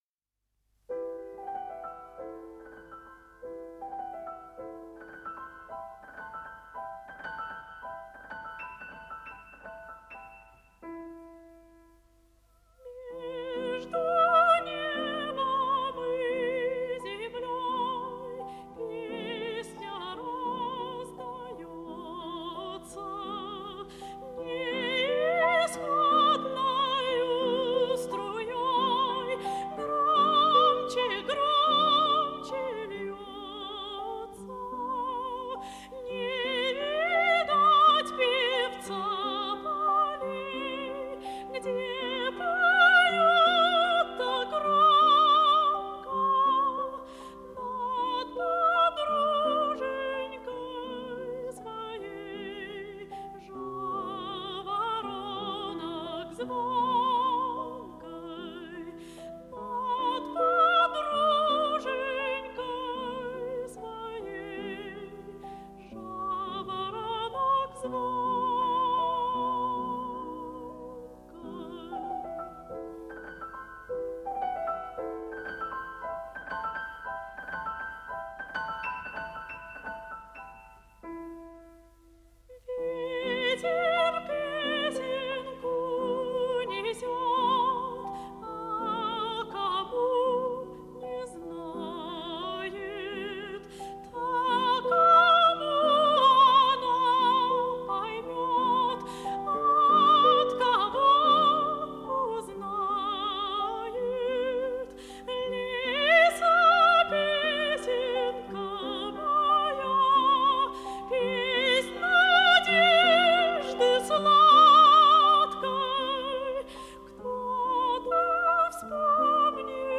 87 лет со дня рождения советской певицы (сопрано), Заслуженной артистки РСФСР Виктории Николаевны Ивановой
Виктория Иванова - великая камерная певица России.